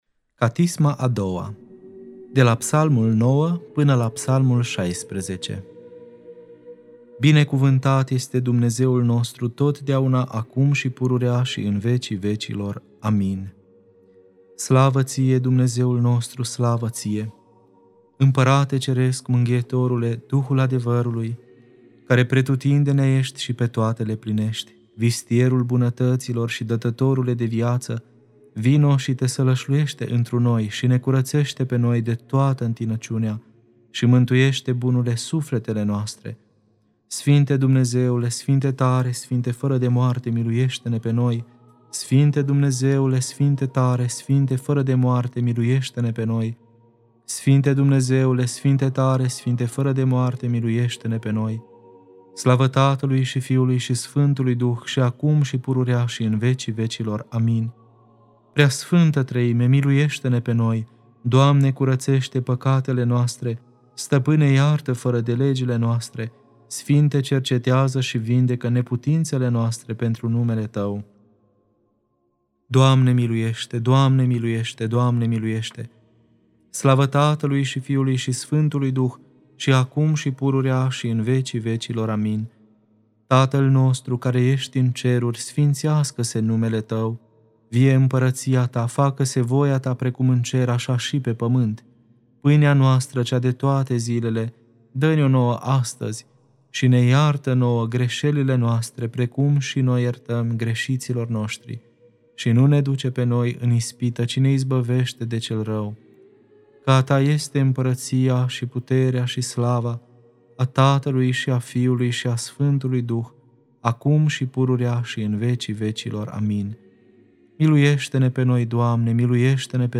Catisma a II-a (Psalmii 9-16) Lectura: Arhim.